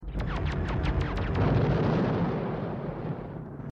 The Black Hole FX - Cygnus laser turret
The_Black_Hole_FX_-_Cygnus_laser_turret.mp3